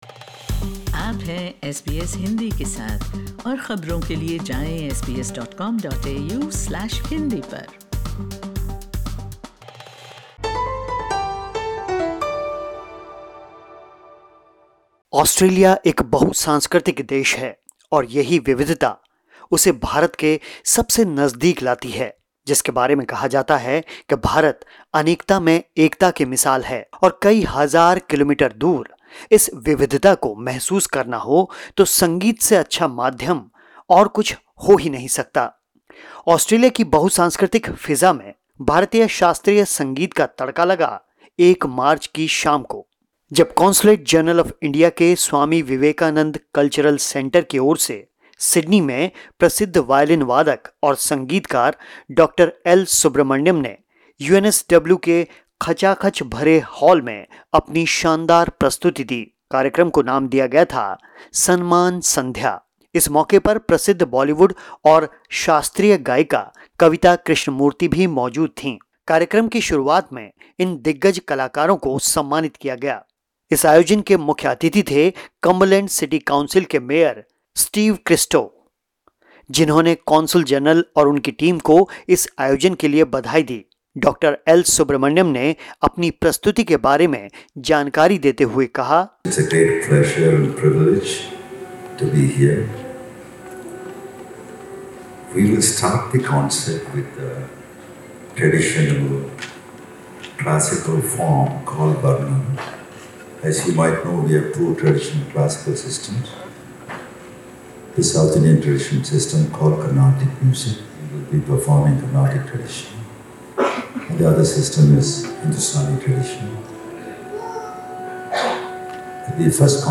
सिडनी में कॉन्सुलेट जनरल ऑफ इंडिया के सौजन्य से सजी एक सुरमयी शाम, जहां प्रसिद्ध संगीतकार और वायलिन वादक डॉक्टर एल सुब्रह्मण्यम ने शानदार प्रस्तुति दी.